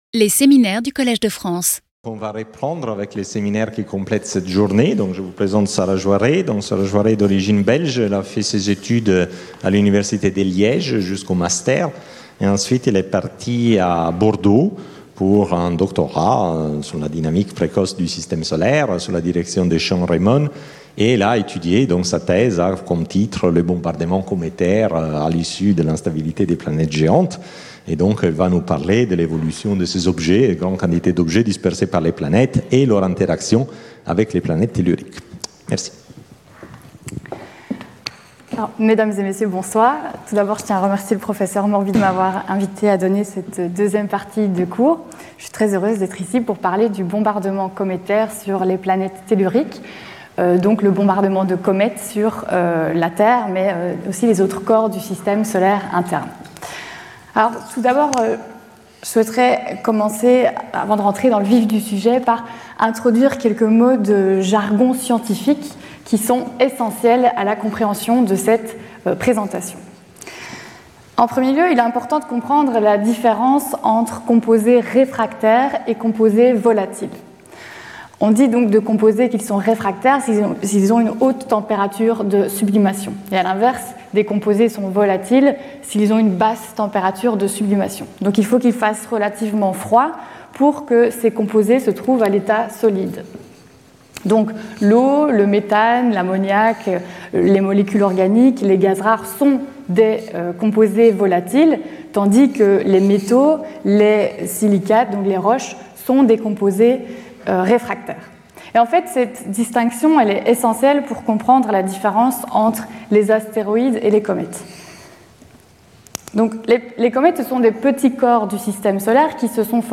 Seminar